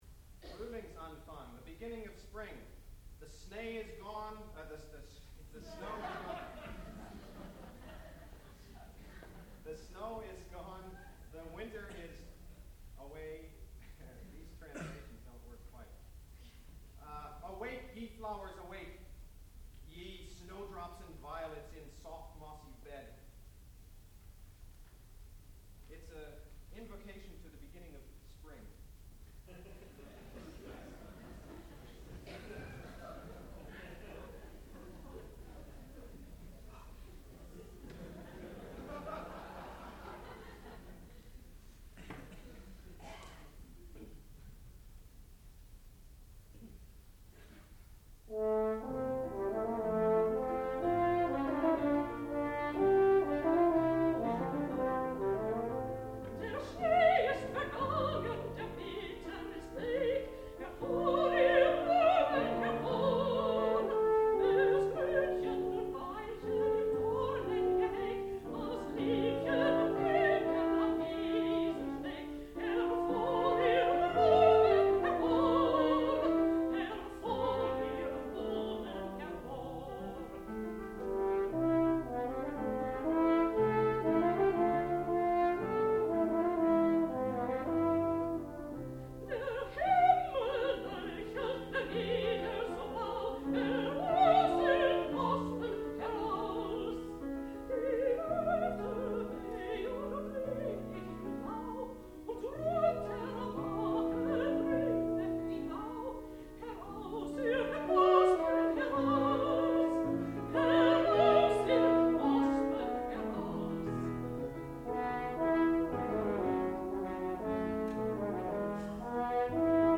sound recording-musical
classical music
piano
mezzo-soprano
horn